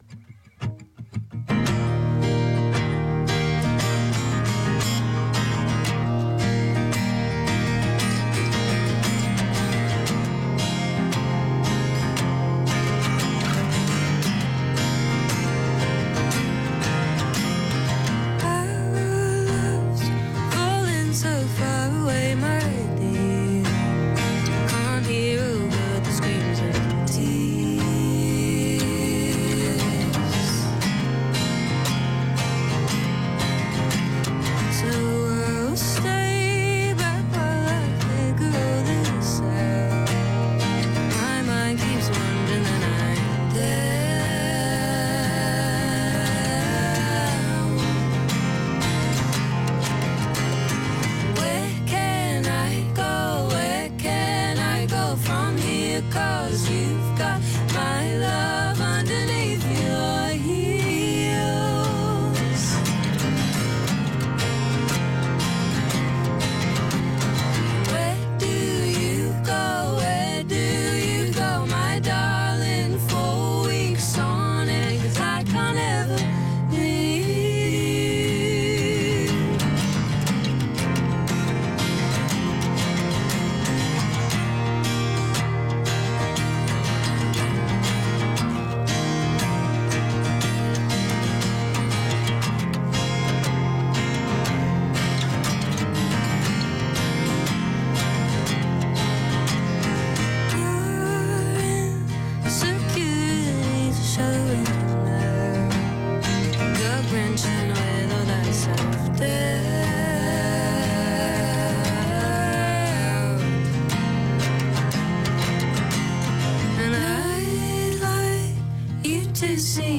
Some chill for a Saturday night.